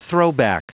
Prononciation du mot throwback en anglais (fichier audio)
Prononciation du mot : throwback